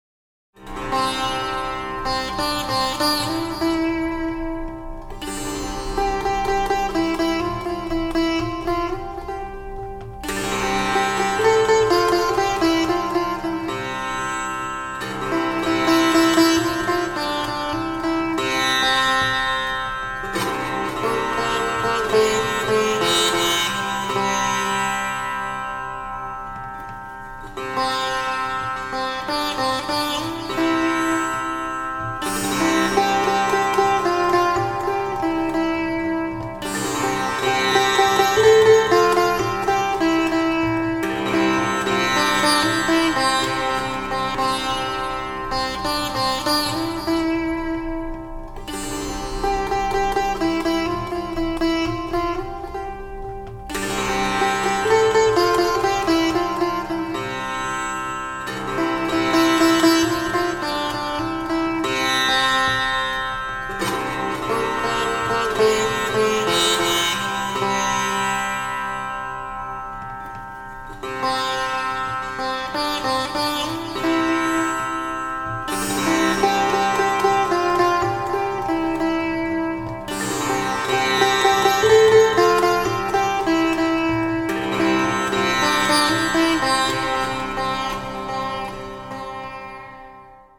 Sitar-Meditation "Raga Raga"